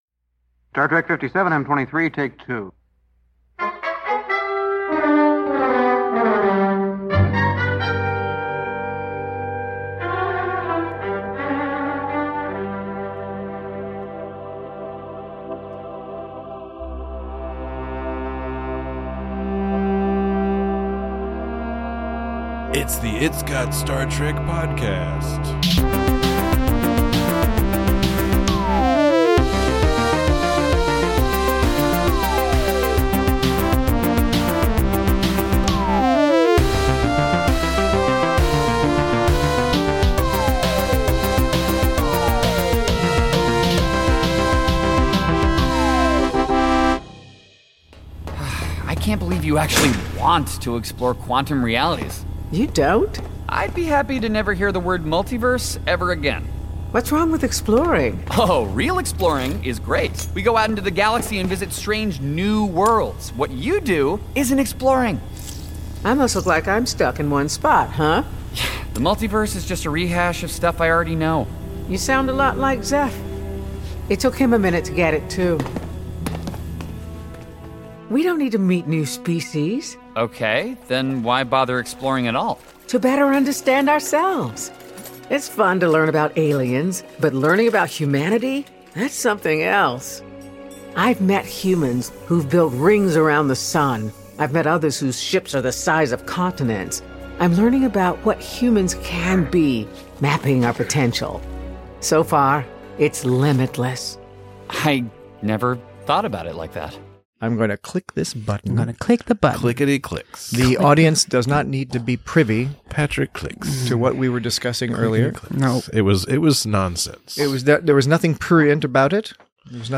Join your two-pipped hosts as they discuss various definitions of upper deckers, clever utilization of alternate-infused nostalgia, and the rapidly diminishing number of standard time units that remain in this utterly brilliant sci-fi series.